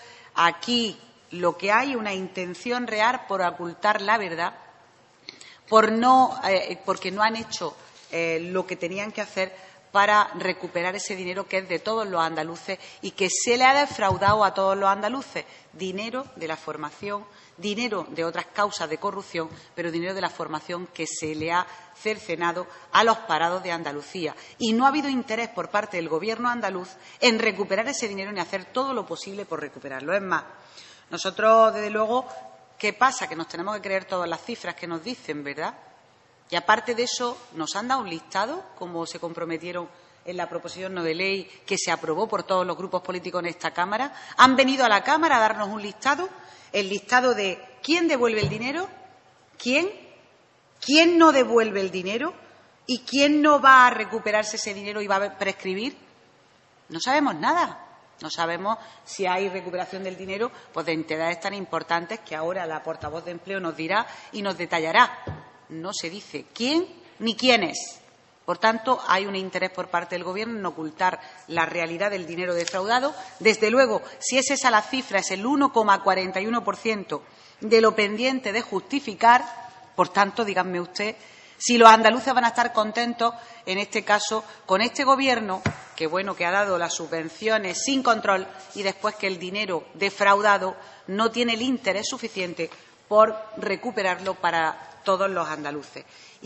Escuche las declaraciones: